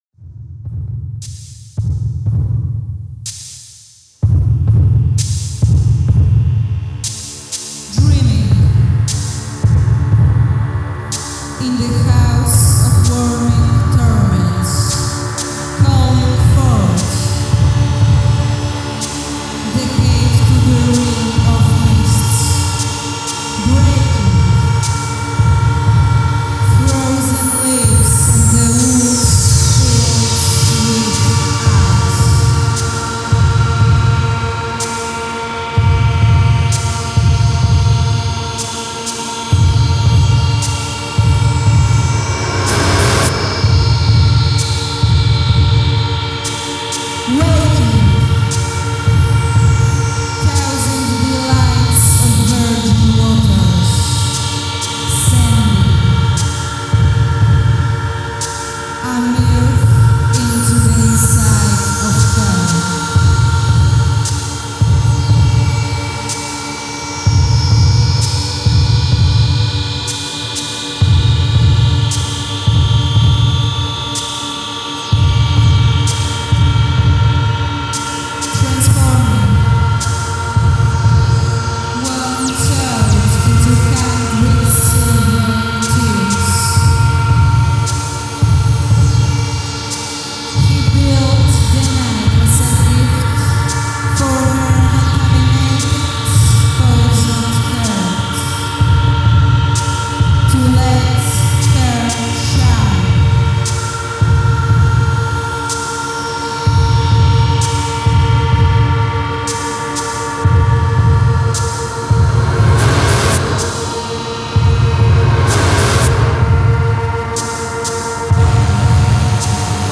Here's a great compilation of dark and neoclassical music
from the new emerging dark-ambiant and neo-symphonic scene